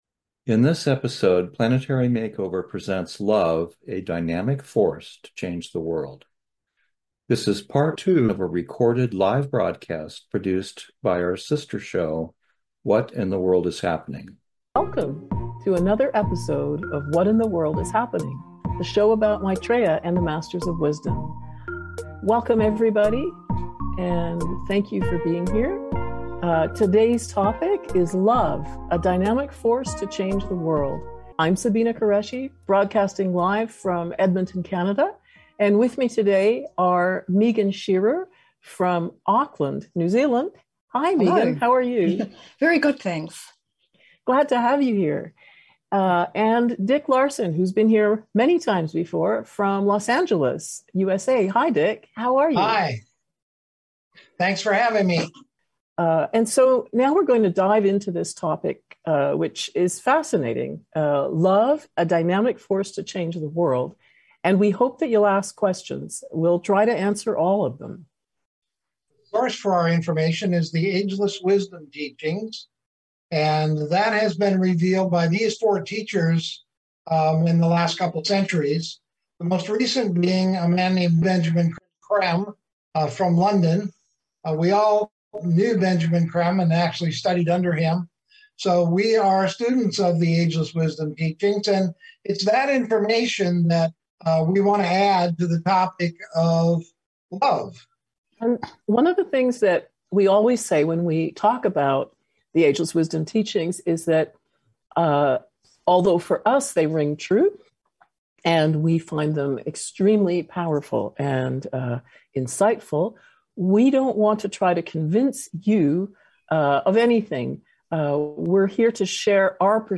In this episode we feature Part 2 of a presentation by our sister show, What in the World is Happening?